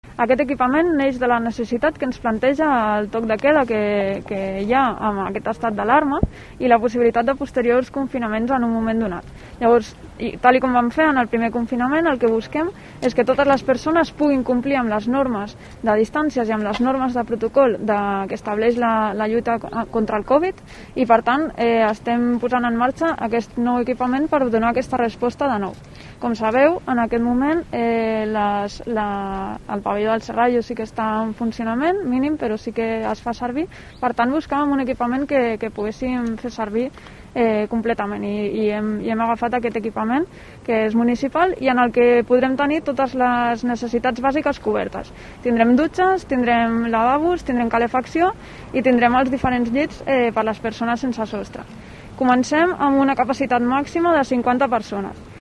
La consellera de Servicios Sociales de Tarragona, Carla Aguilar, sobre las características del albergue